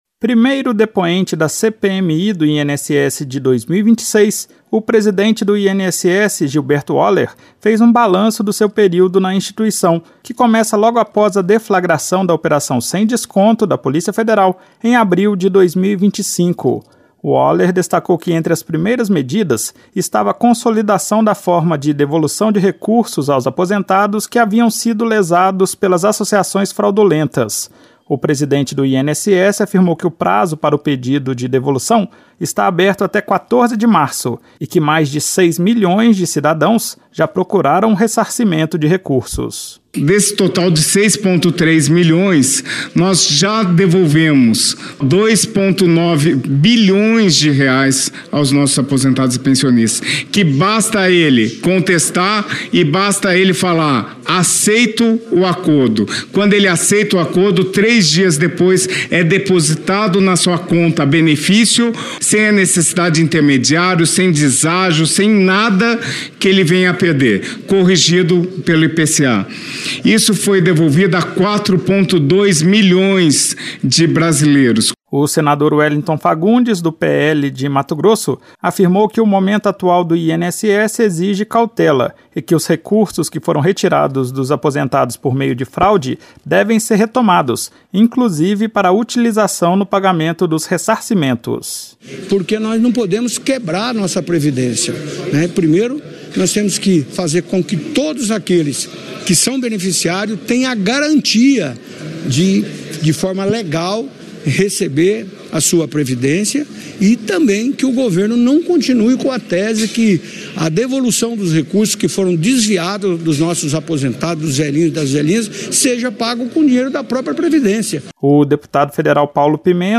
Em CPMI, presidente do INSS destaca ressarcimento a 4 milhões de aposentados